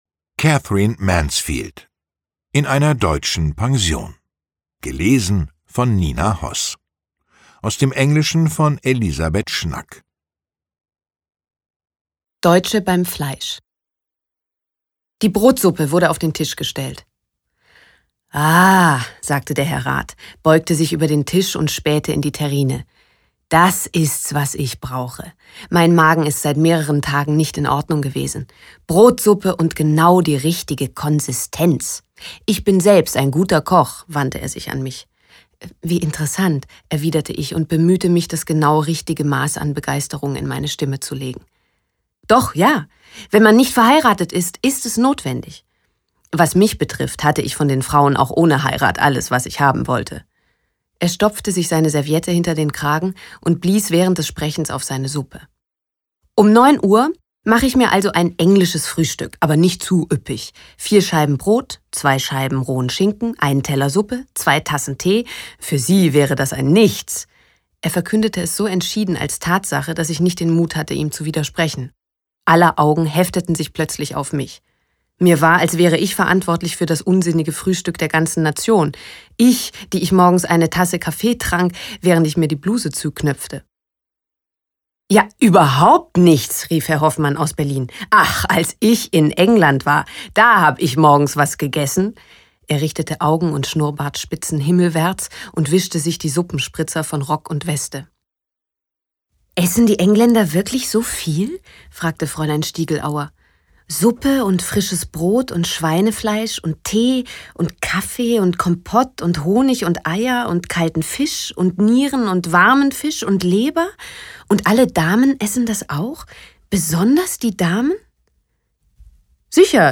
Lesung mit Nina Hoss (1 mp3-CD)
Nina Hoss (Sprecher)